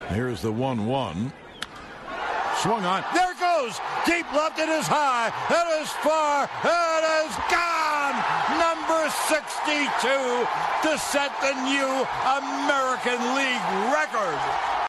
PBP-Yankees-1-0-Judge-62nd-HR-SHORT.mp3